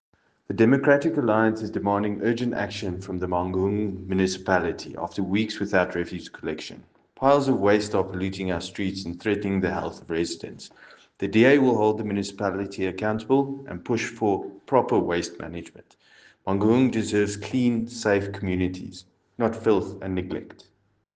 Afrikaans soundbites by Cllr Paul Kotzé and